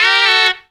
2 NOTE RIFF.wav